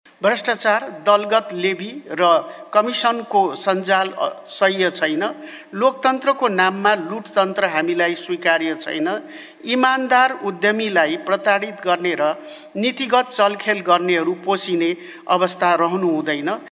काठमाडौँमा आयोजित राष्ट्रिय आर्थिक बहस २.० कार्यक्रममा निजी क्षेत्रका प्रतिनिधिहरूलाई विशेष सम्बोधन गर्दै उनले इमानदार उद्यमीहरूलाई प्रताडित गर्ने र नीतिगत चलखेल गर्नेहरू पोसिने अवस्था अब रहन नसक्ने स्पष्ट पारिन्।